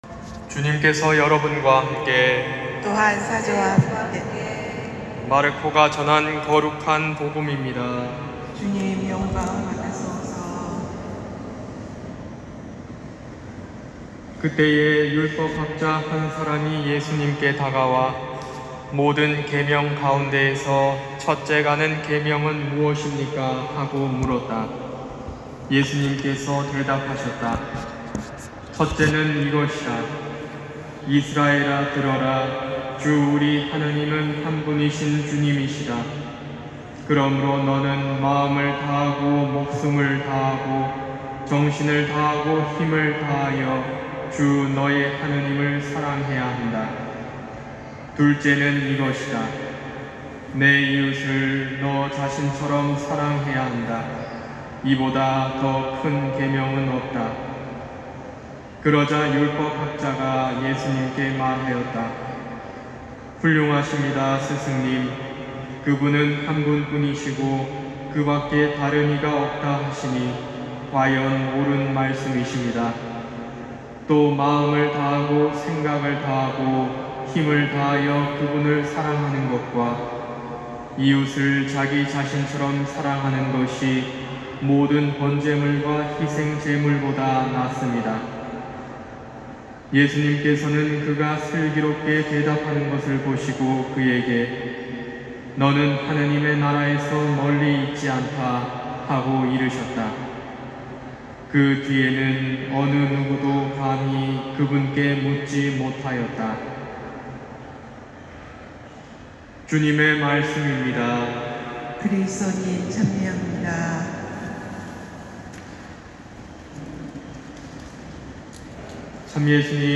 241102 신부님 강론 말씀